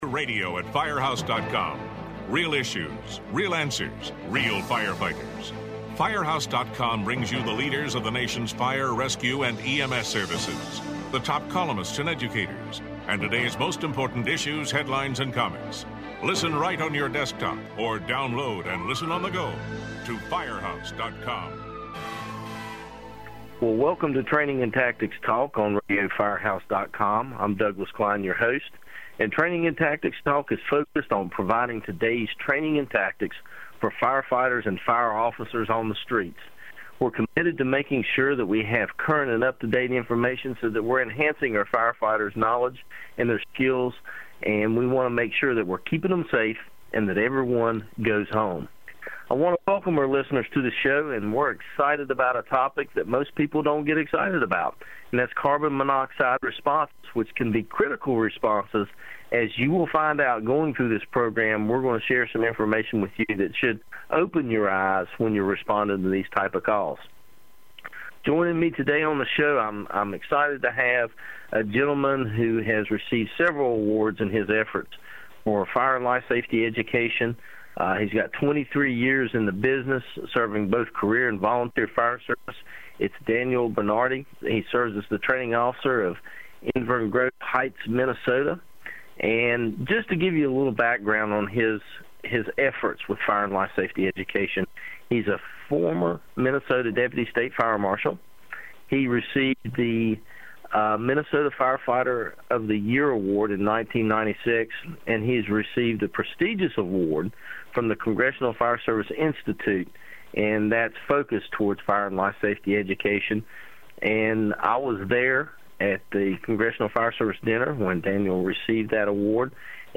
These two seasoned training officers will share real life incidents that will make you realize that there is a need for specialized training when you are responding in these type of conditions. From carbon monxide and chimney emergencies to a different mindset with winter driving, these conditions bring new rules to the game.